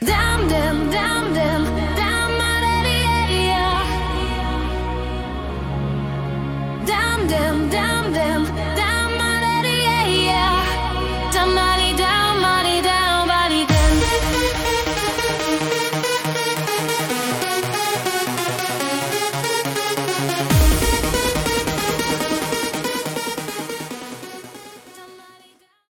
Style : Trance, Eurodance